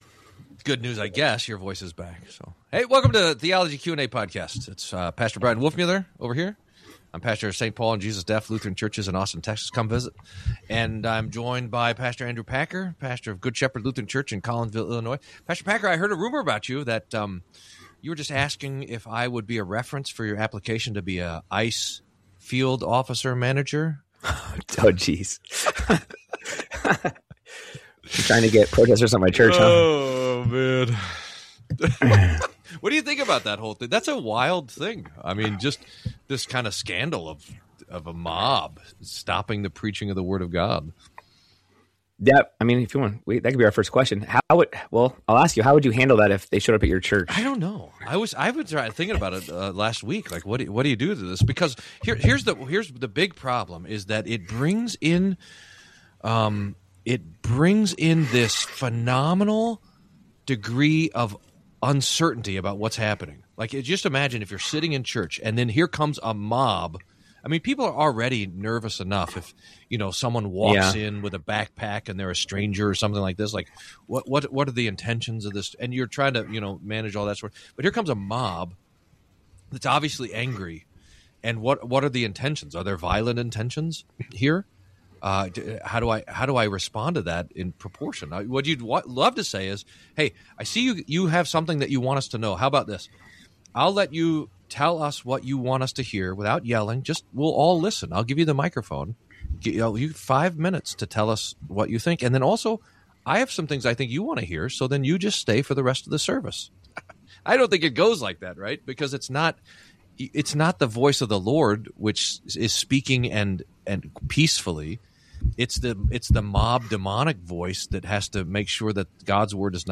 Theology Q&A: Protests in Church, Caring for the Homeless, Amillennialism, What's after Death? Is Speeding a Mortal Sin? What about Ash Wednesday?